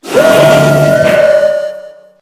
Cri_0889_EB.ogg